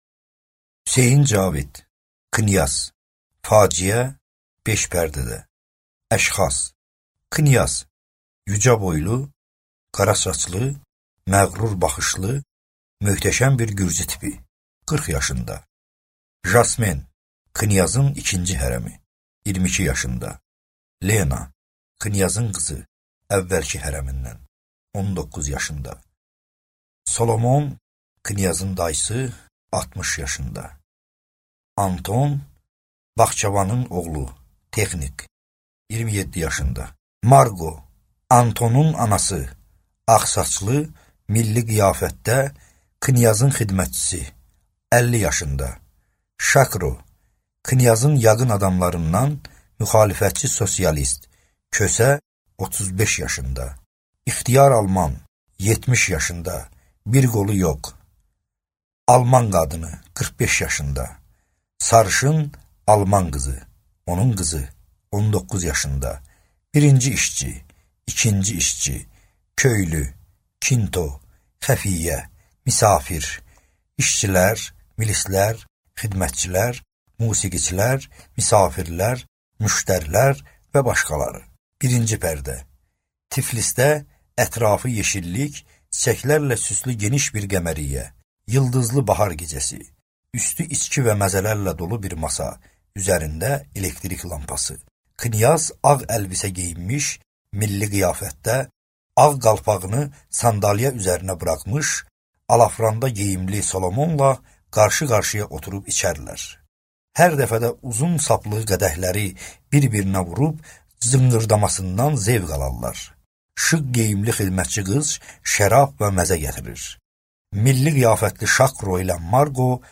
Аудиокнига Knyaz | Библиотека аудиокниг